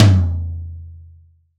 L-TOM15C-1.wav